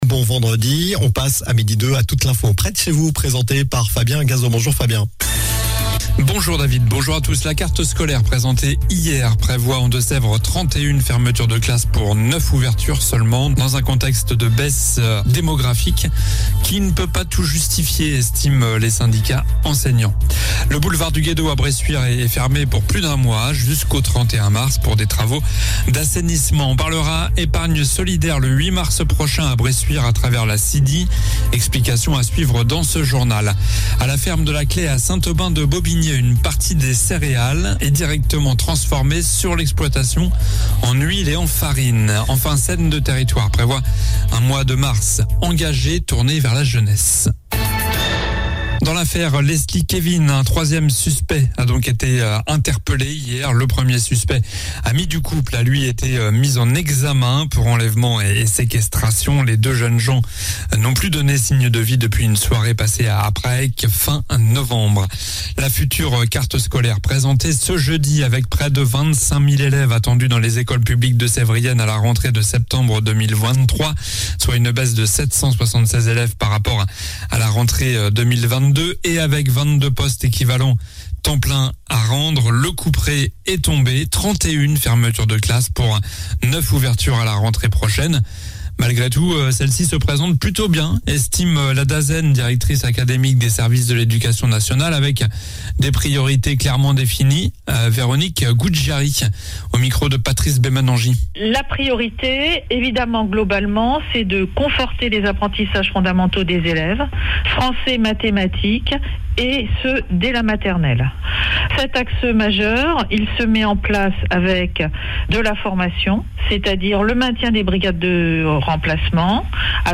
Journal du vendredi 03 mars (midi)